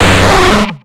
Fichier:Cri 0230 XY.ogg — Poképédia
Cri d'Hyporoi dans Pokémon X et Y.